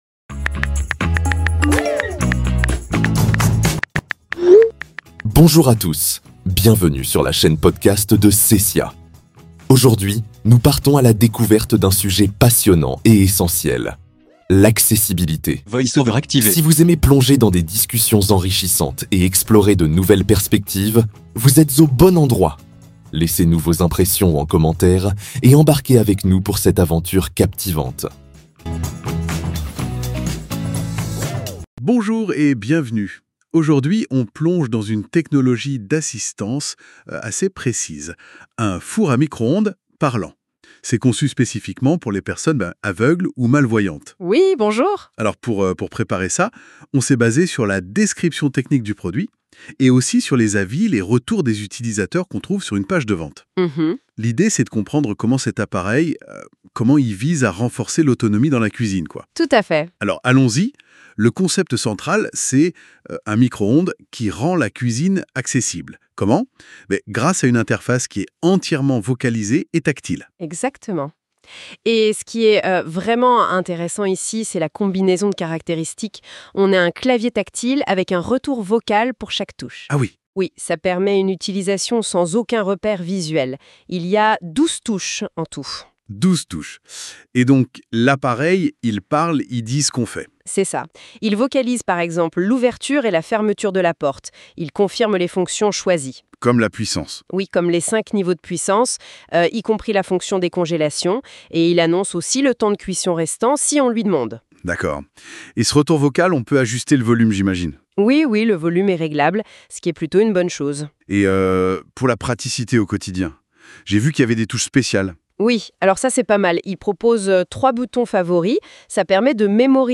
MicroOnde-parlant.mp3